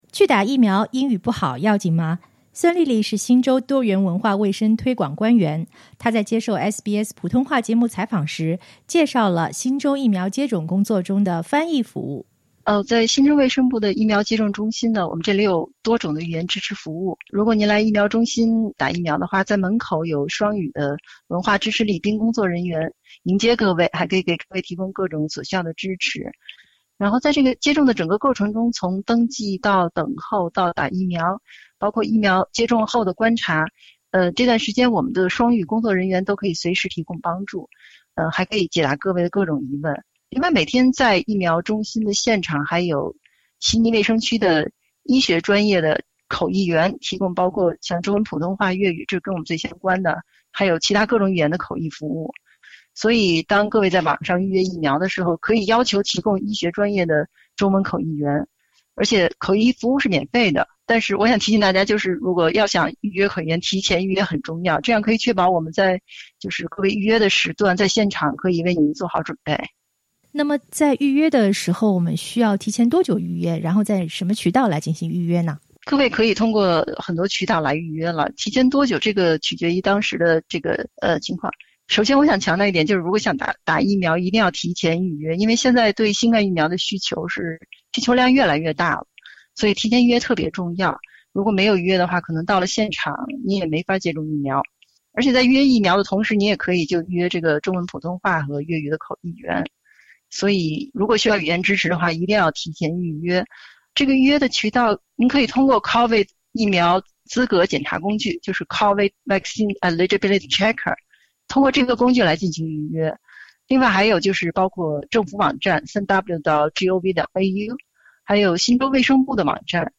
她在接受SBS普通话节目采访时介绍了新州疫苗接种工作中的翻译服务。